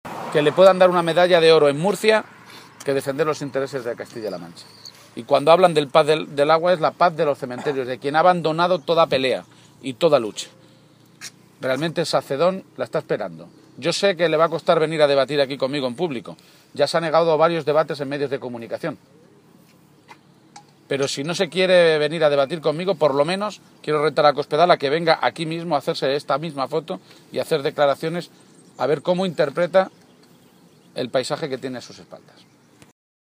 García-Page se pronunciaba de esta manera esta mañana, en la localidad alcarreña de Sacedón, donde se ubica el pantano de Entrepeñas, uno de los dos grandes pantanos de cabecera del Tajo de los que sale el agua para el trasvase al Segura.